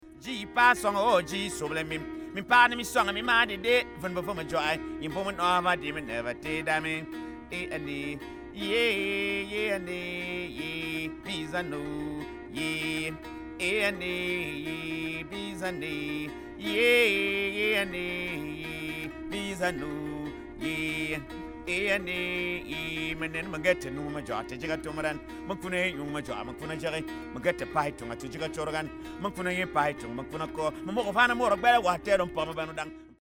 Mvet du Gabon
Le mvet du Gabon (ou du Cameroun) est le principal représentant du type, à corde pincées.